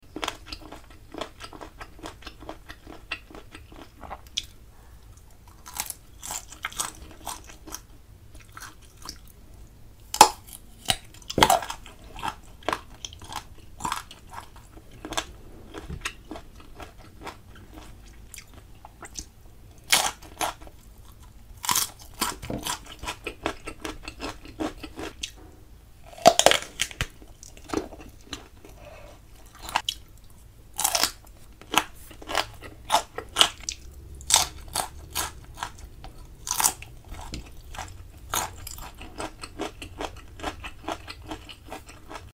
RAW VEGGIES ASMR MUKBANG 🤤🍆🥒